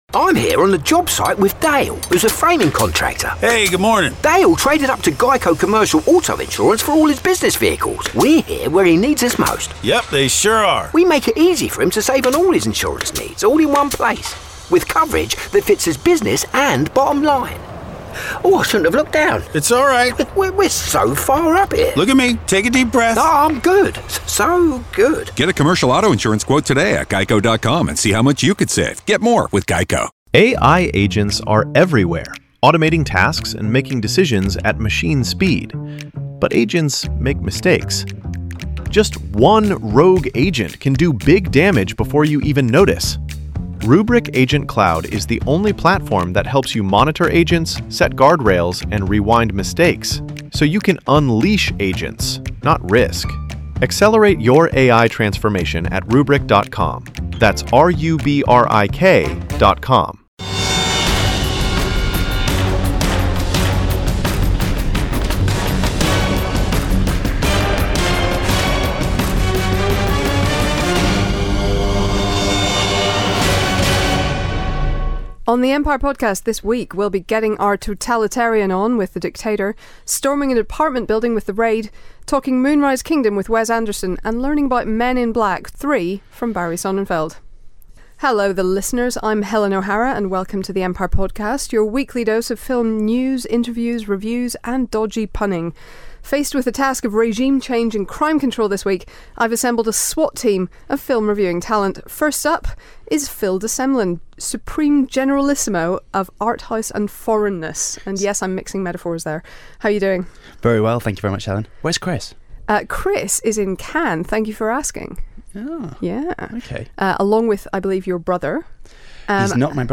For our twelfth episode, we discuss the return of a certain bear from Peru and the rest of the week's key bits of movie news, then we've got our reviews of this week's releases, including The Raid and The Dictator. Plus, Wes Anderson himself pops round for a chat, as does Men In Black director Barry Sonnenfeld.